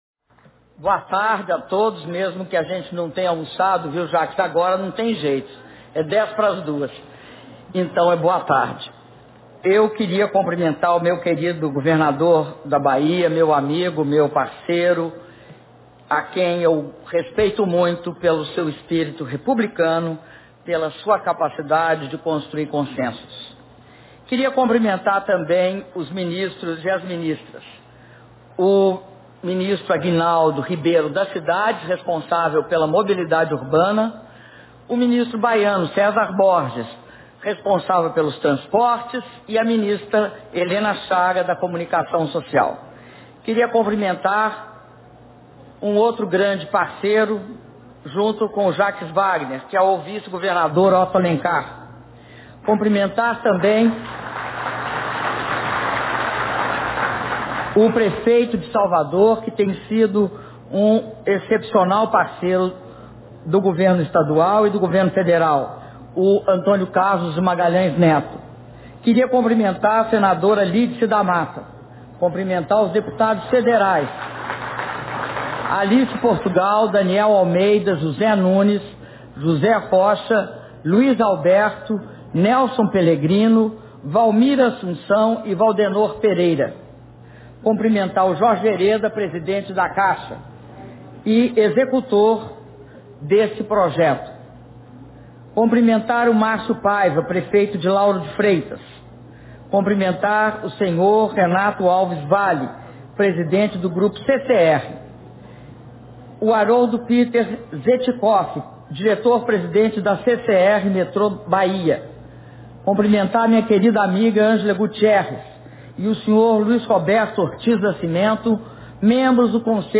Discurso da Presidenta Dilma Rousseff na cerimônia de anúncio de investimentos do PAC Mobilidade Urbana e assinatura dos contratos de metrô e de corredores - Salvador/BA